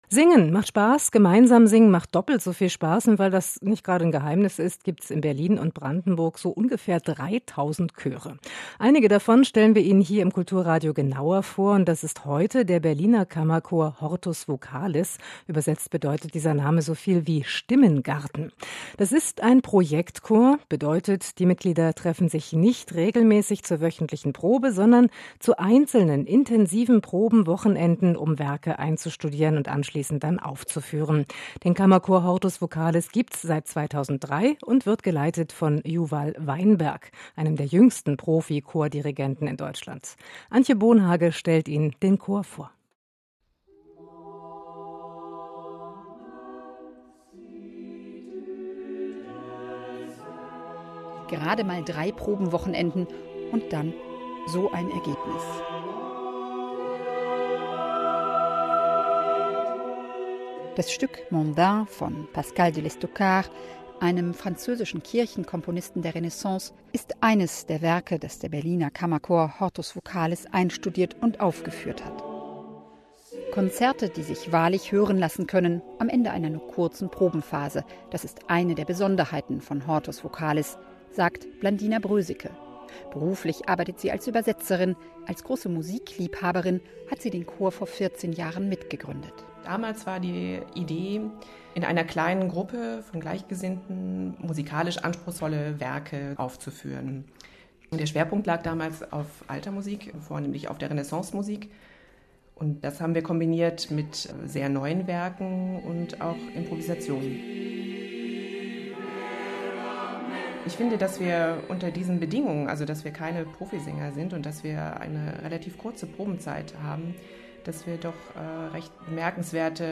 “Radiobeitrag zu hortus vocalis.” – 2017
hortus vocalis Berliner Kammerchor – Interview